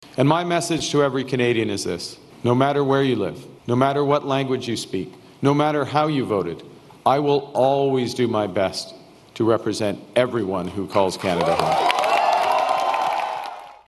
Carney continued on with a very direct message to the country.